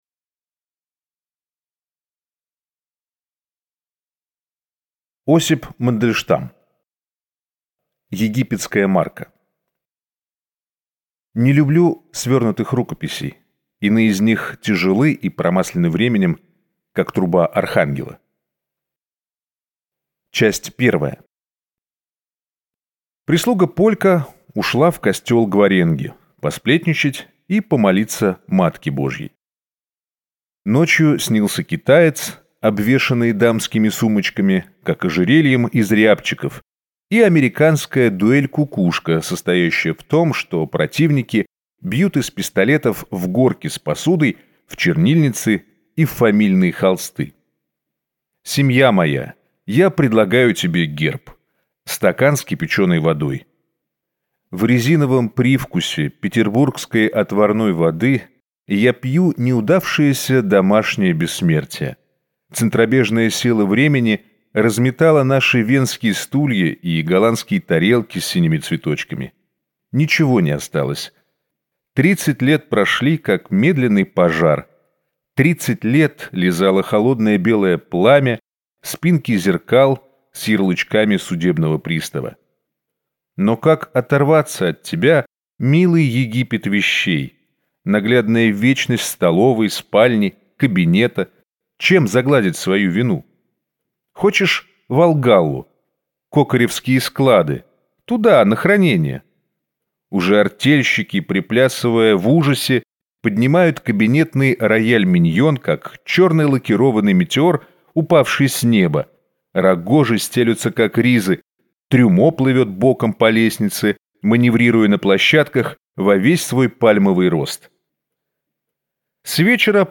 Аудиокнига Египетская марка | Библиотека аудиокниг